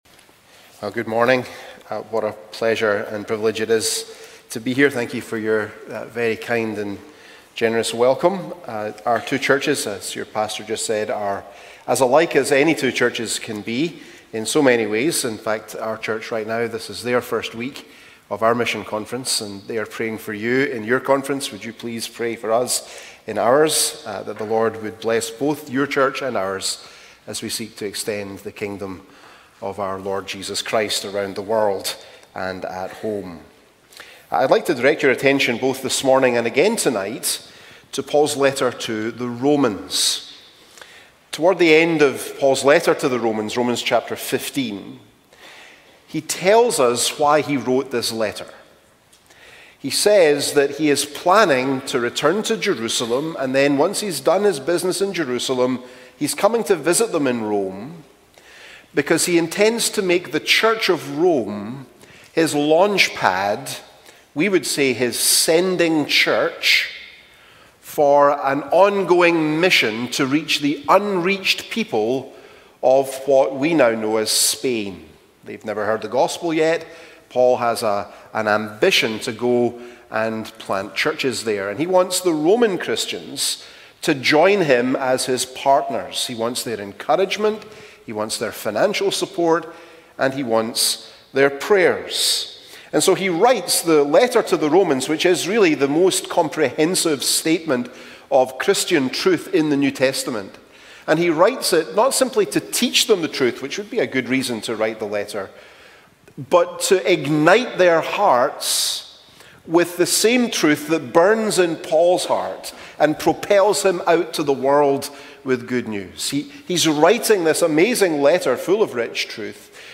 Sermons - First Presbyterian Church of Augusta
From Series: "2024 Missions Conference"